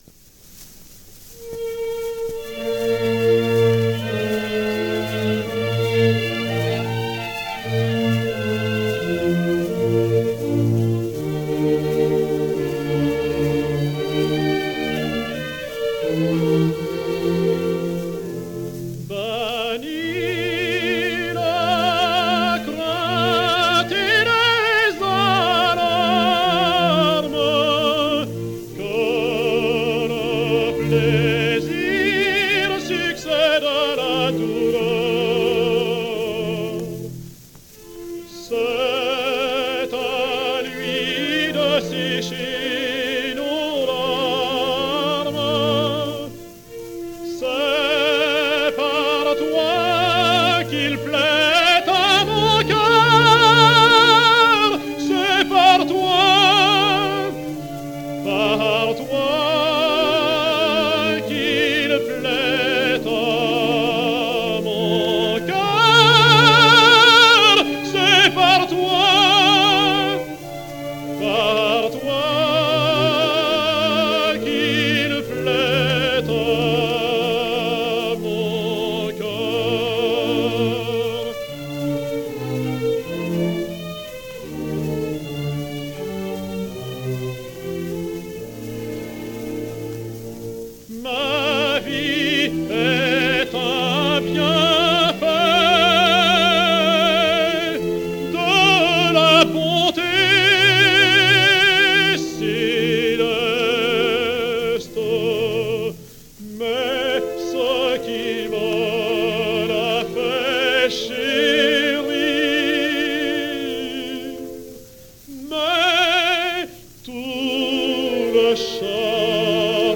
Georges Thill sings Alceste: